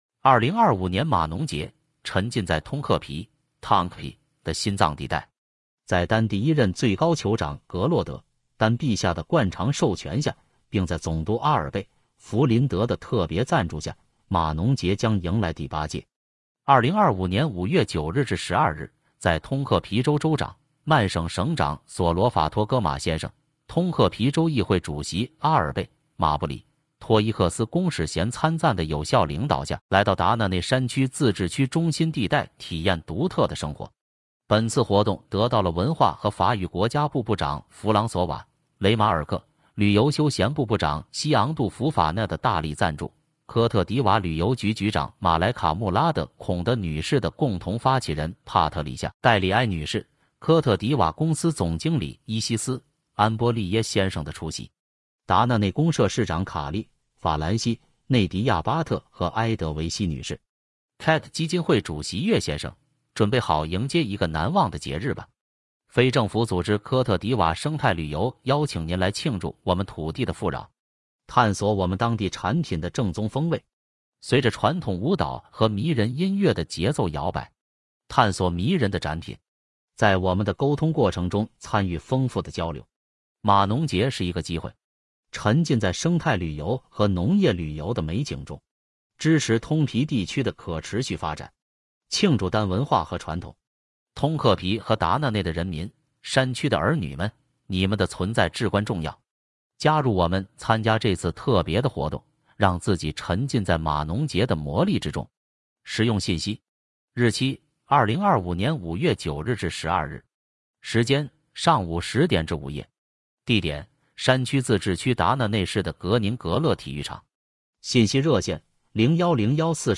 在2025年”马农节”启动仪式上：